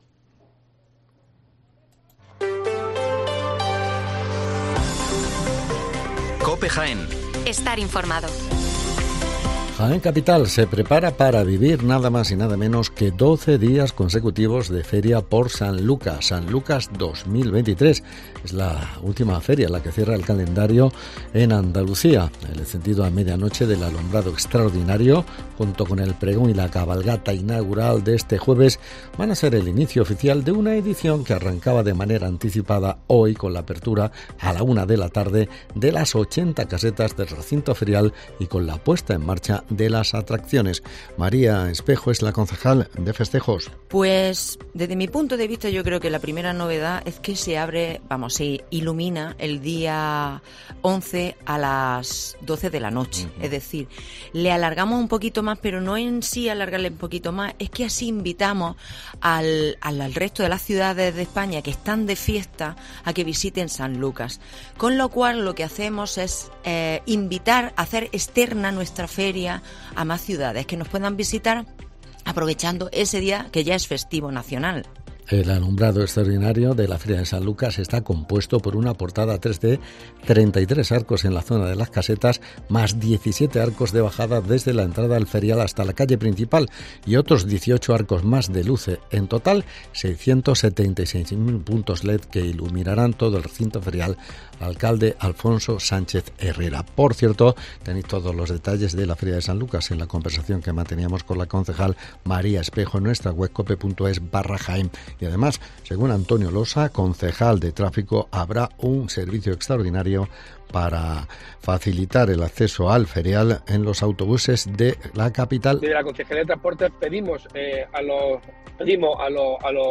Las noticias locales del 11 de octubre de octubre de 2023